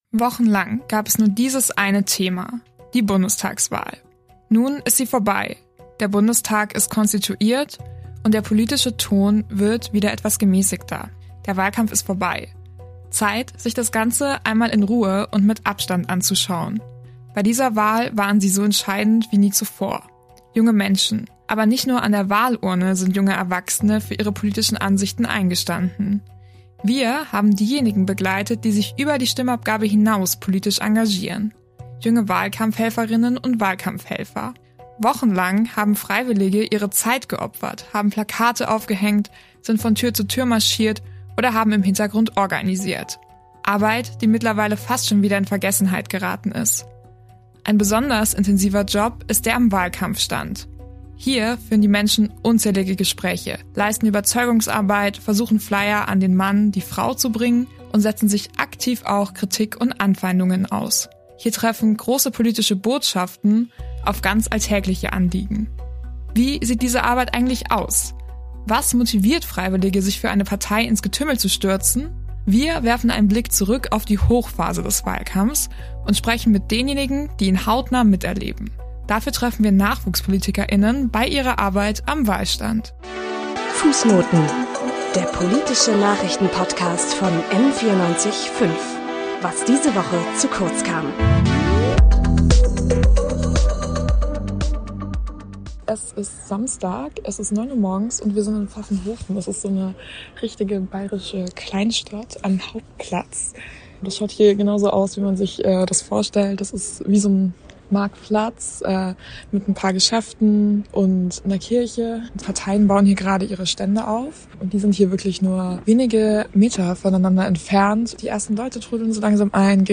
In dieser Fußnotenfolge begleiten wir Nachwuchspolitiker:innen im Wahlkampf in Bayern und werfen einen Blick hinter die Wahlstände der großen Parteien.